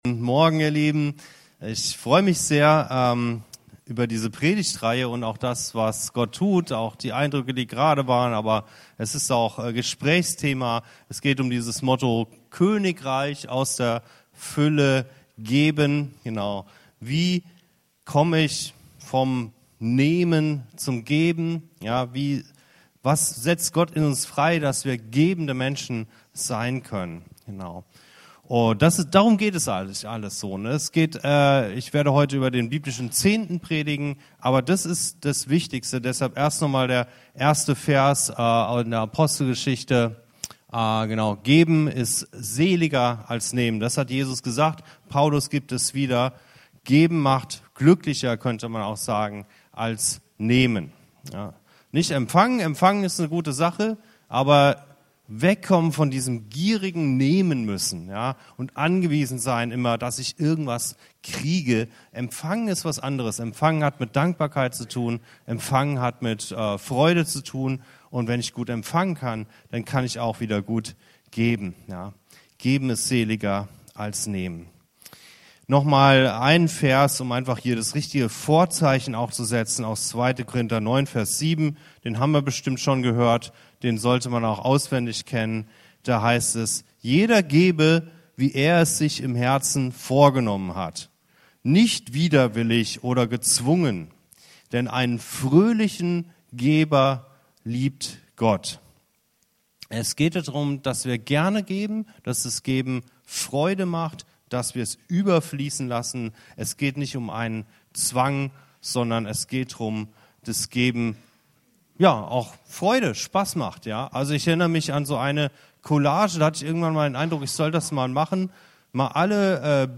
Es lädt uns ein, aus Vertrauen und Freude zu geben, statt aus Zwang – mit dem Versprechen, dass Gott selbst uns reichlich segnen wird. Diese Predigt ermutigt dazu, das Geben als Teil unserer Beziehung zu Gott zu sehen und ihn in diesem Bereich auf die Probe zu stellen.